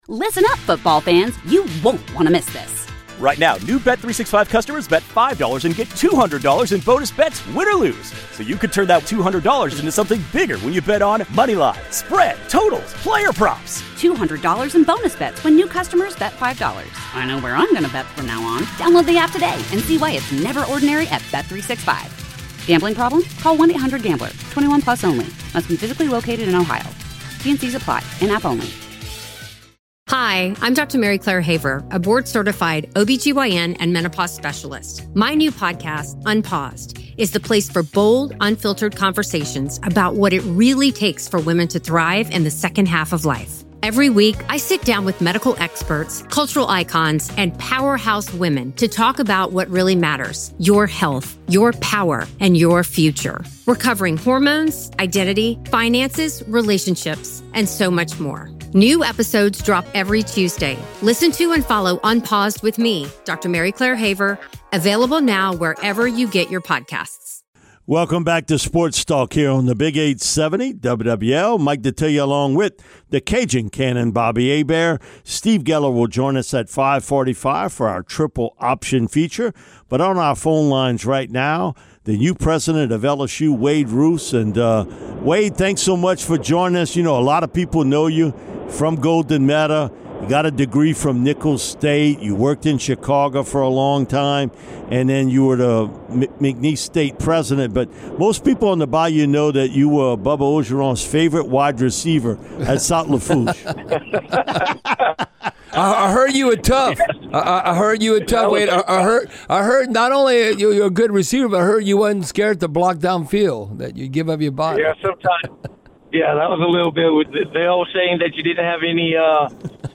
In an exclusive interview with WWL